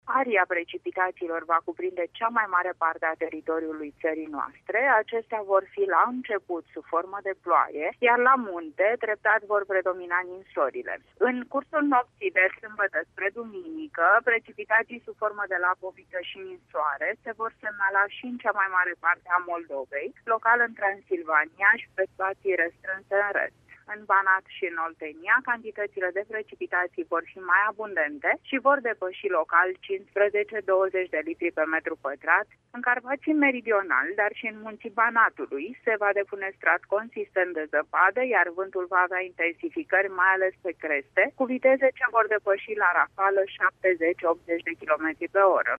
Meteorologul